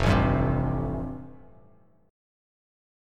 Fadd9 chord